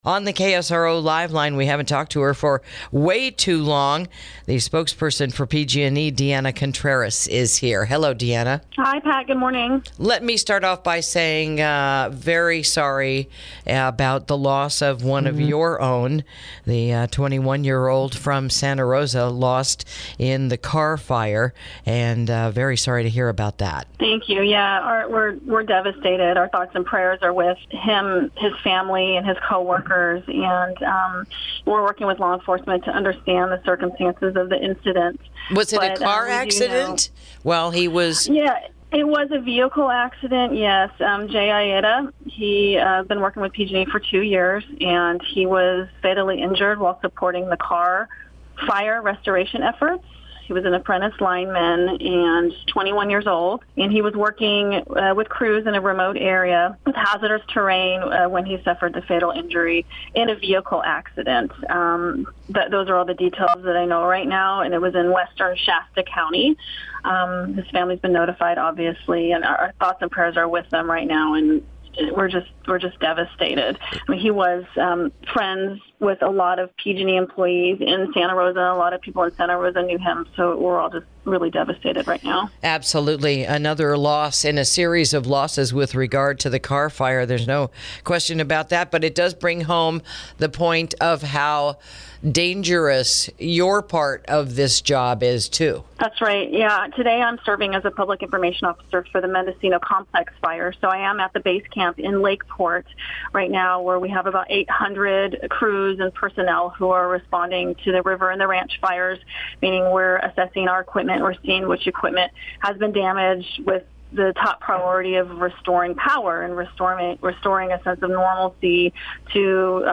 INTERVIEW: PG&E's New Community Wildfire Safety Program Hopes to Prevent Future Wildfires | KSRO 103.5FM 96.9FM & 1350AM